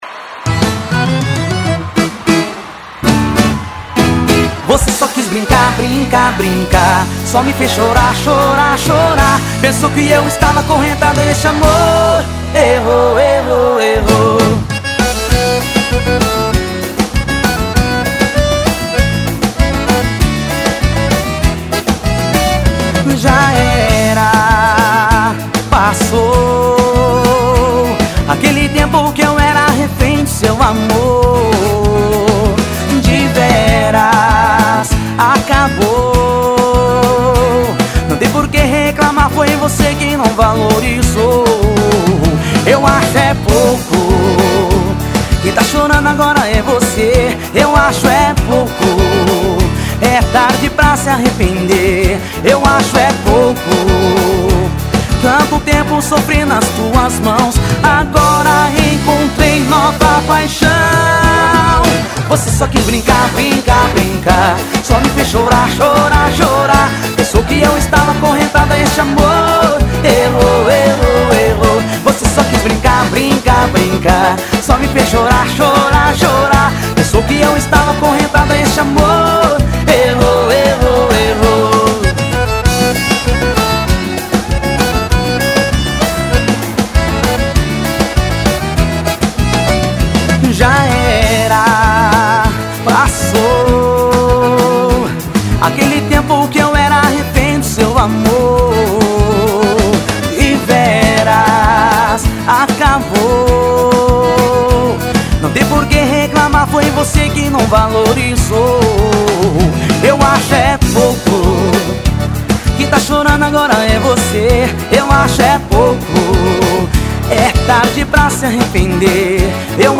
CD AO VIVO.